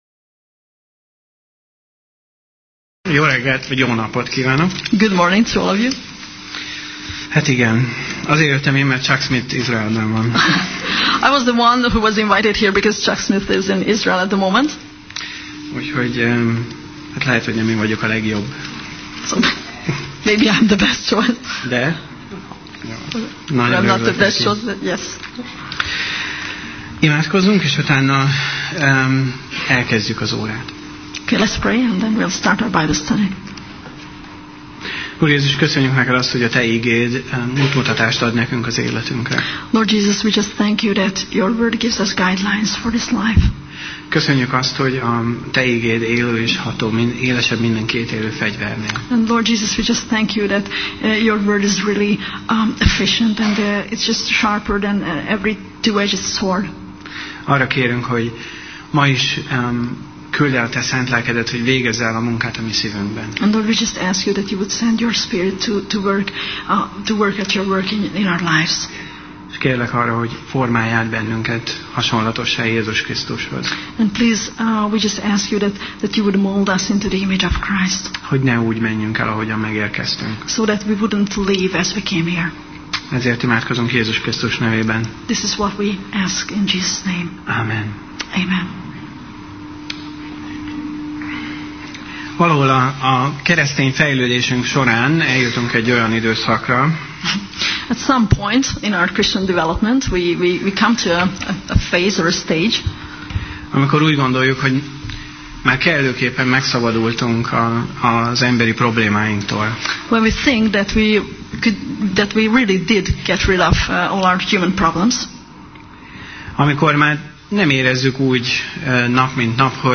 Tematikus tanítás Alkalom: Vasárnap Reggel